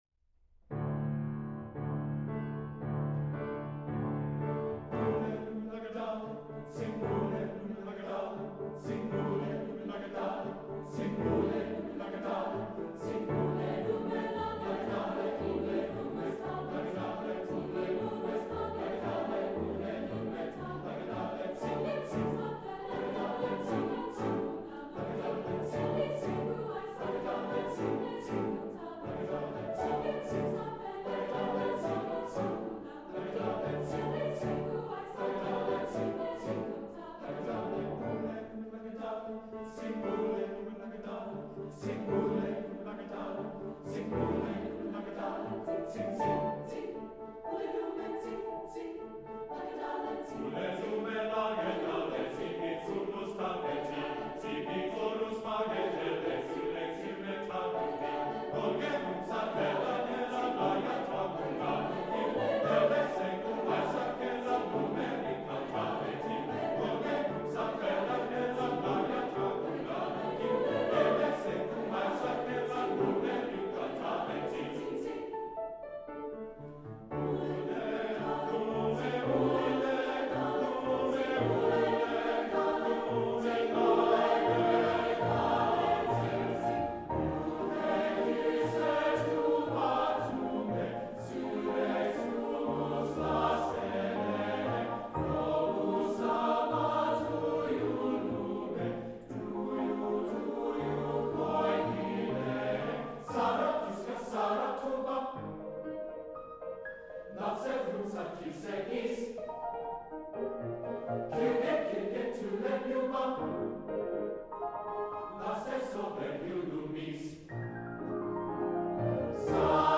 Composer: Traditional Estonian
Voicing: SATB